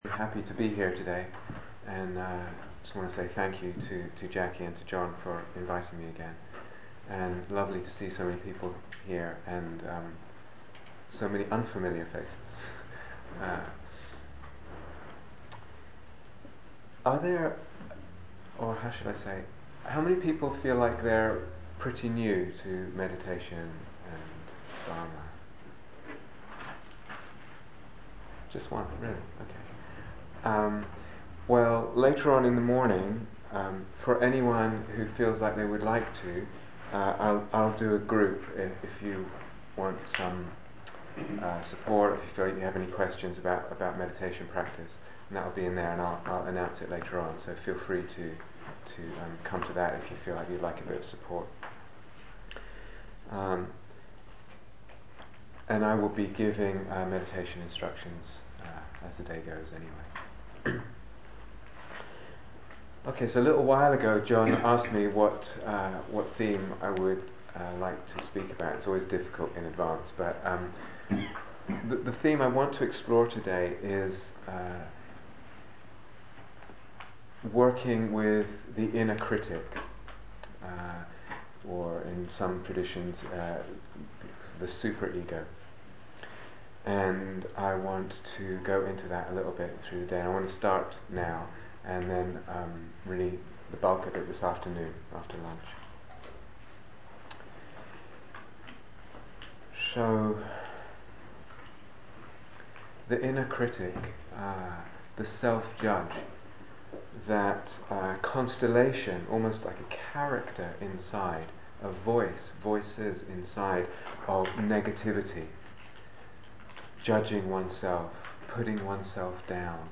Liberation from the Inner Critic - Opening Talk
Retreat/Series Cambridge Day Retreats 2010